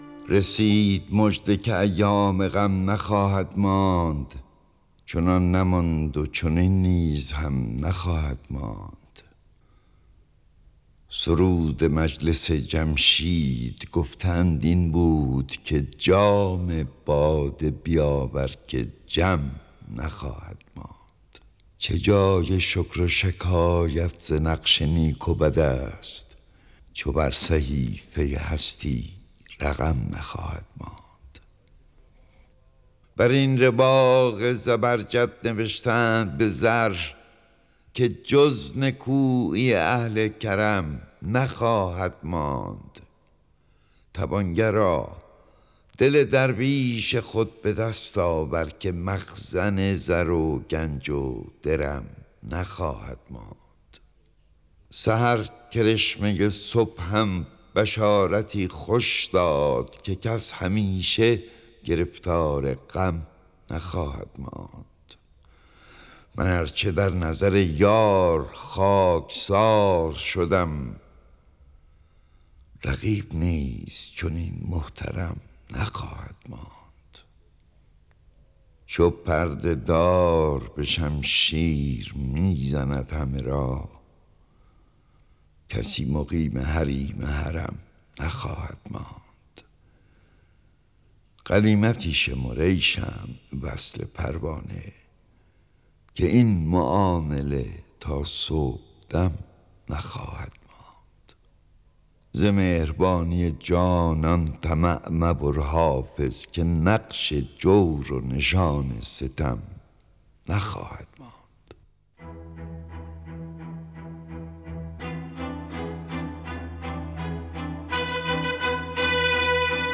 دانلود دکلمه رسید مژده که ایامِ غم نخواهد ماند با صدای احمد شاملو با متن دکلمه
گوینده :   [احمد شاملو]